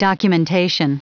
Prononciation du mot documentation en anglais (fichier audio)
Prononciation du mot : documentation